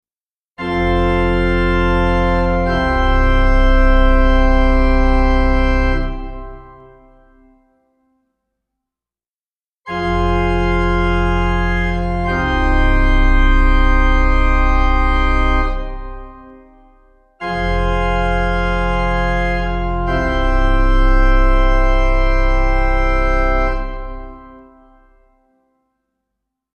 Each MP3 file contains three simple two chord amens:
1. Major key
2. Minor key
2. Minor to major key.